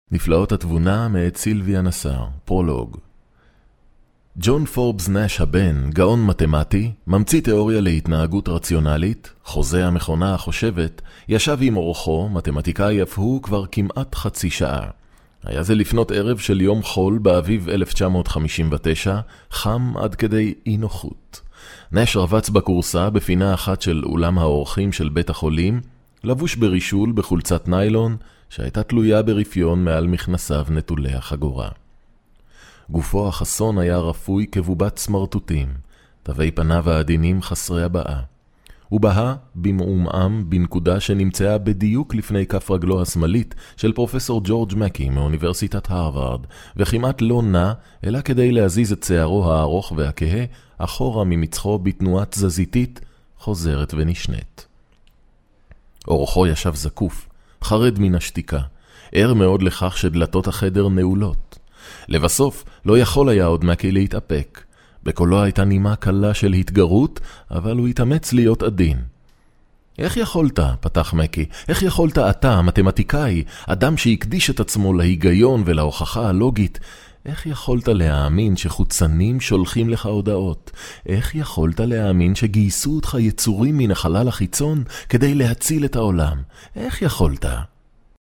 Very experienced Hebrew Voice over artist from Israel. own studio. provide production facilities.
Sprechprobe: Industrie (Muttersprache):